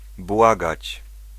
Ääntäminen
US : IPA : [bɛg]